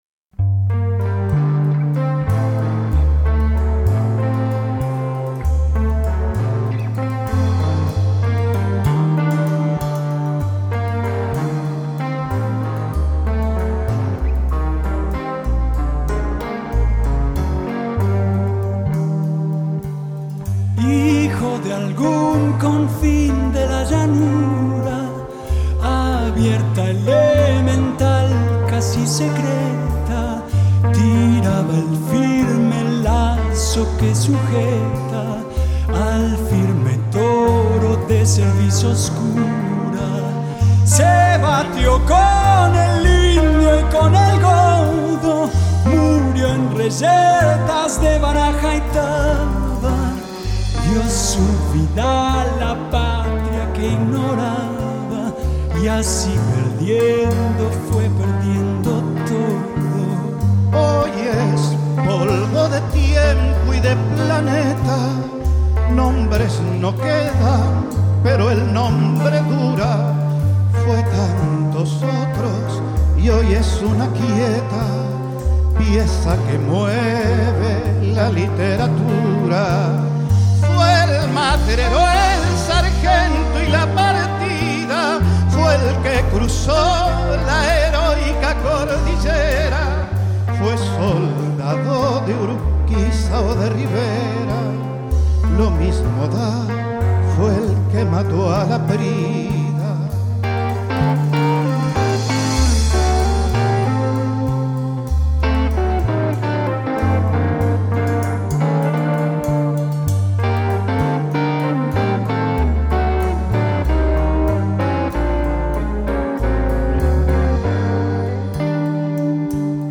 voz
bajo
guitarra
teclados
batería